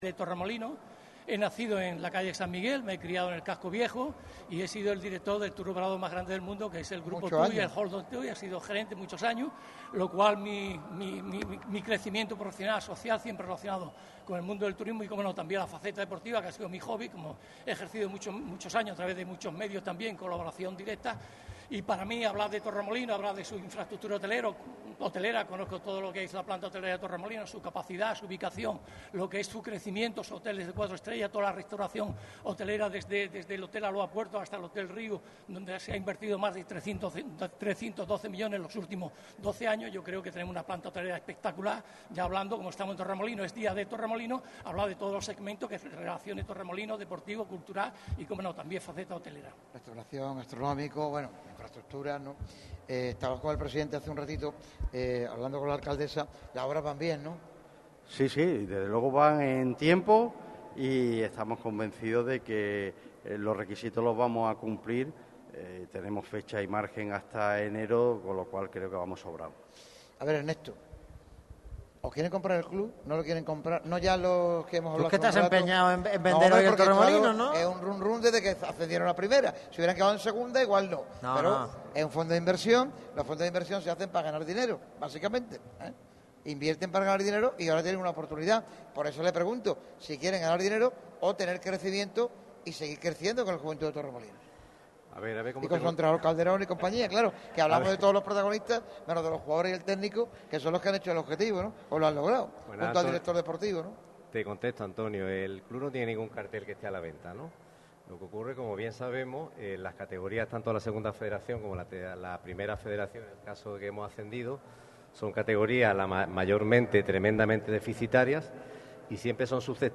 TERTULIA-1-1.mp3